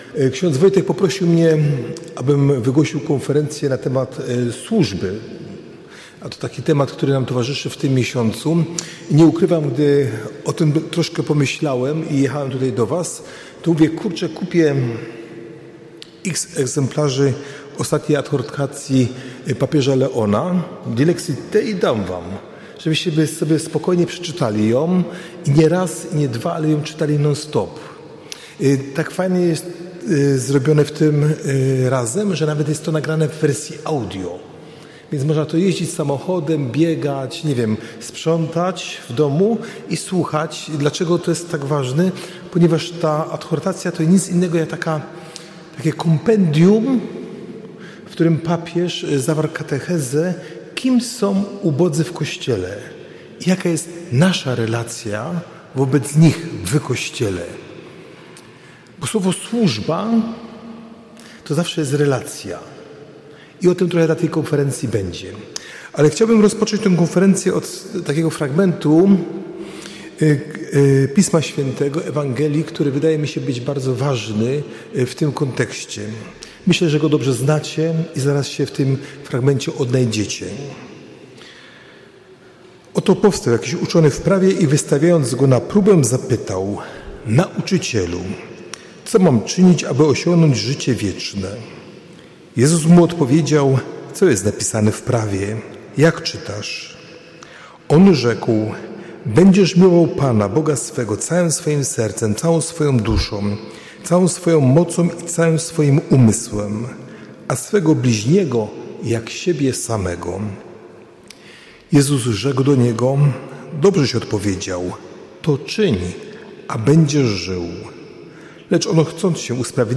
Grupa 33 - Duszpasterstwo osób stanu wolnego - Małżeństwo w nauczaniu Kościoła - 24.11 konferencja